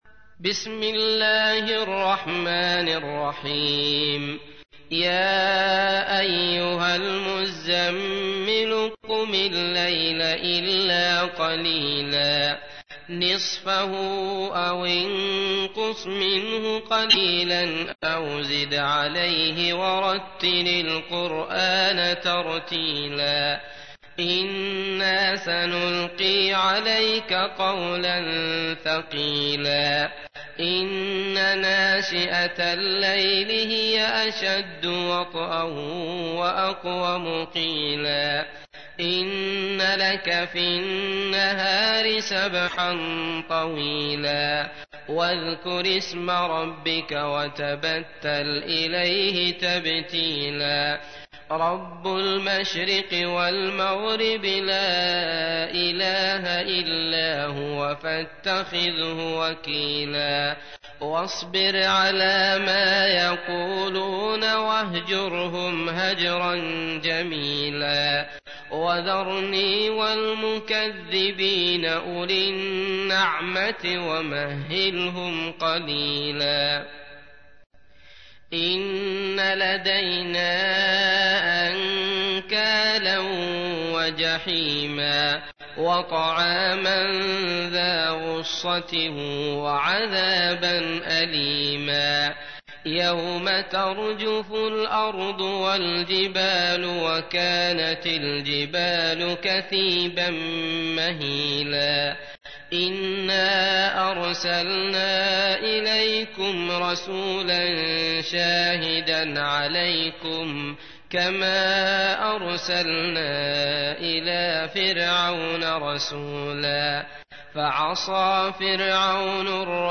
تحميل : 73. سورة المزمل / القارئ عبد الله المطرود / القرآن الكريم / موقع يا حسين